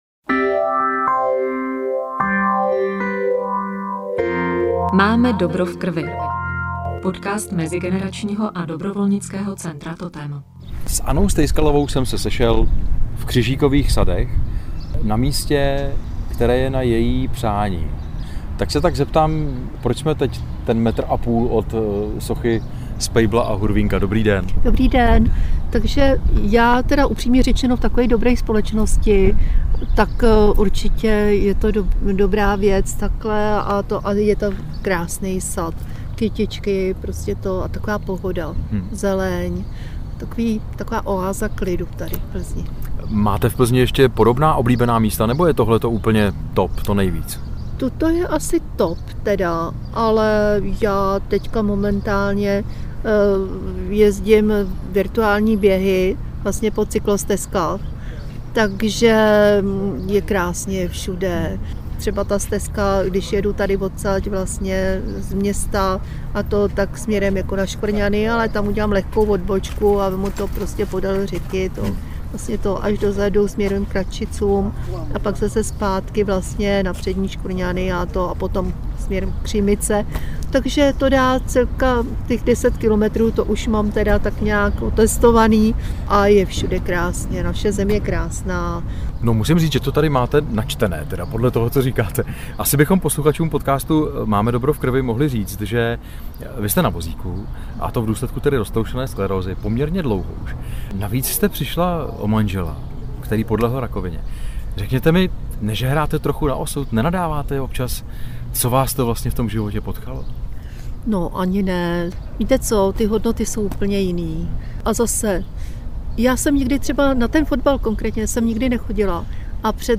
Přinášíme úžasný rozhovor plný inspirací pro aktivní občanský život